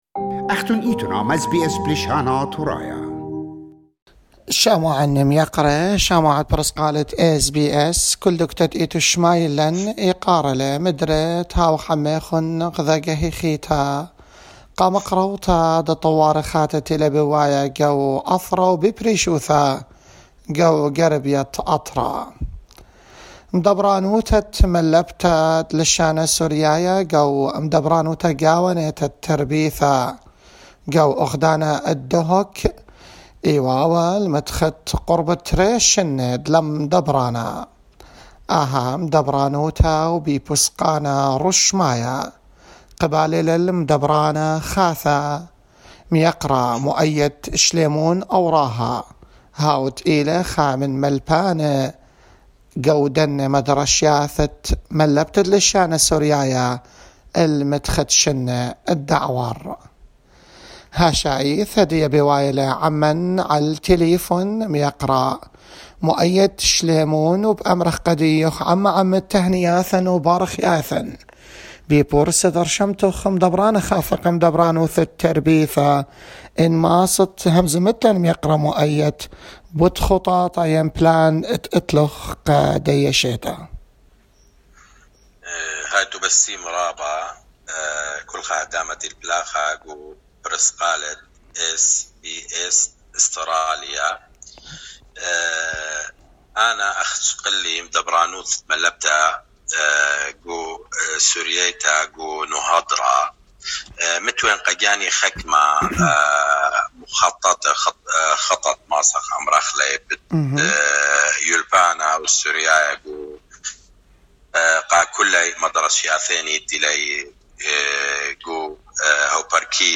met with him in Dohuk to inform us about his plans and the problems that he will work on solving.